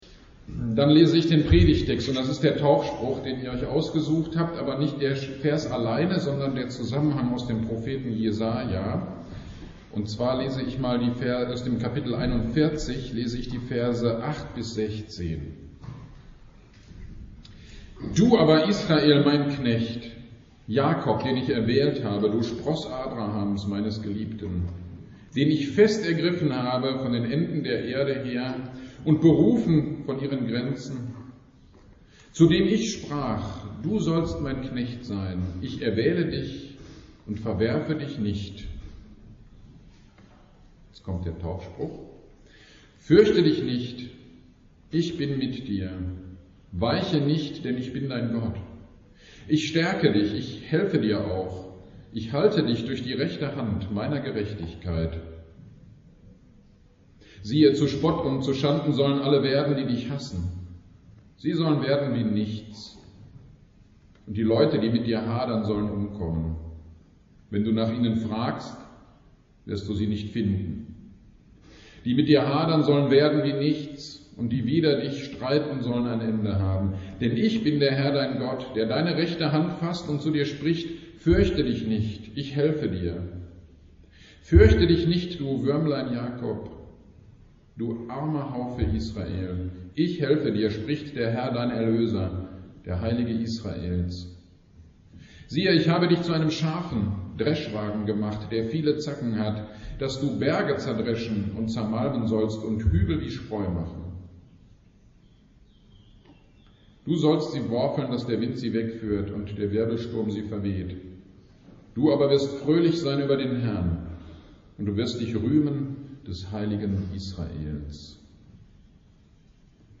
Tauf-GD am 17.07.22 Predigt zu Jesaja 41,10 - Kirchgemeinde Pölzig
Predigt-zu-Jesaja-4110.mp3